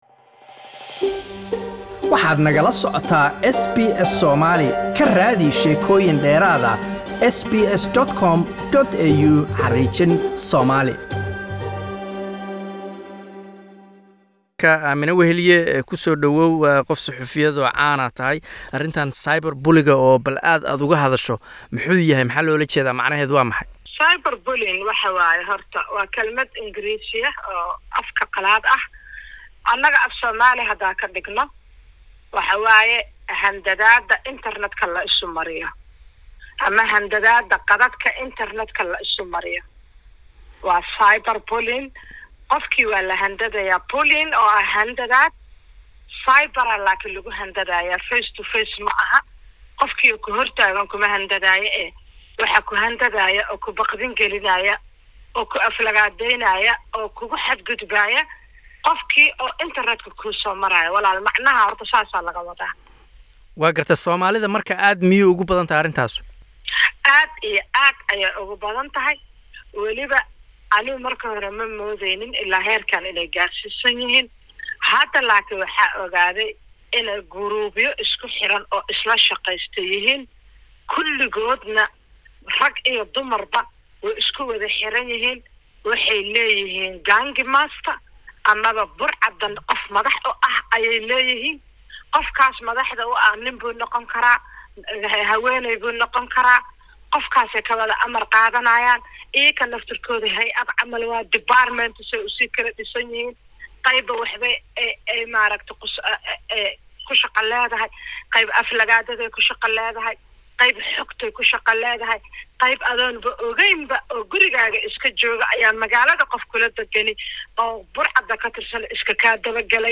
Waraysi dheer halkan ka dhagayso.